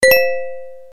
SMS Tone